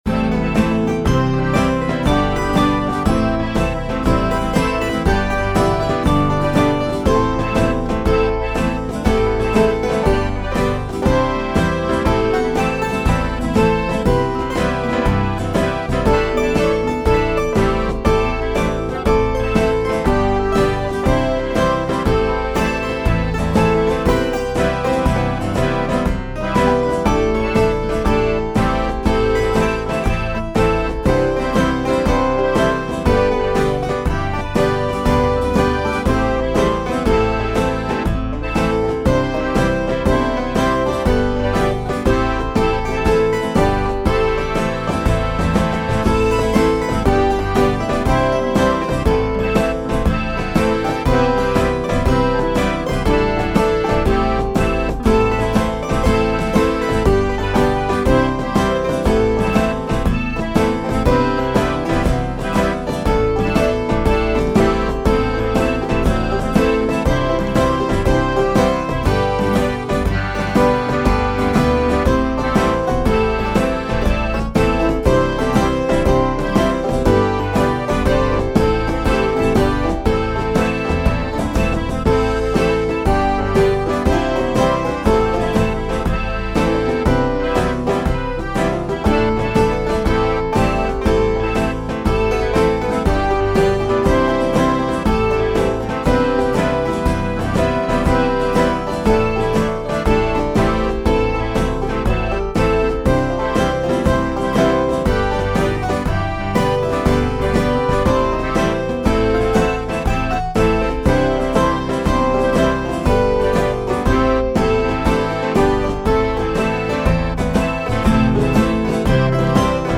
midi-demo 1